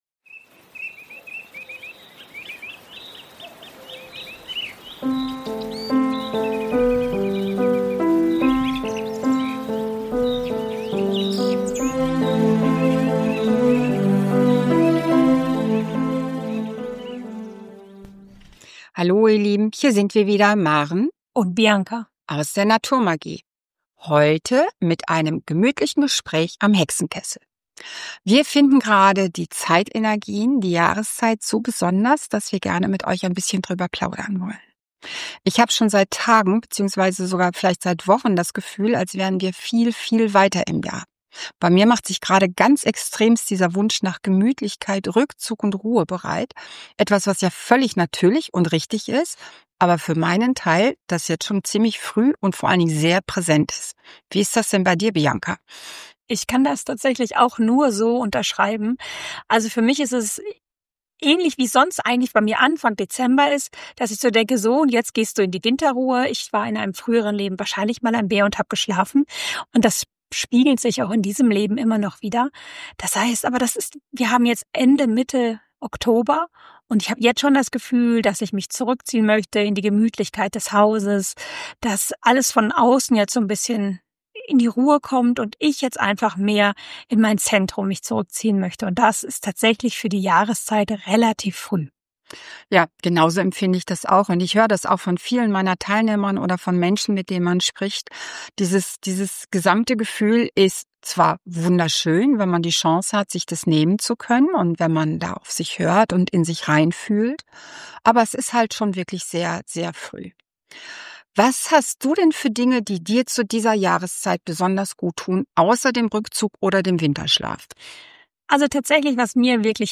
Rauhnachtsspezial Märchen & Meditation & Räuchern & Ritual Dieses Jahr laden wir dich ein, die Rauhnächte auf ganz besondere Weise zu erleben.
Für eine heimelige, magische Stimmung, die dich sanft durch die Rauhnächte trägt.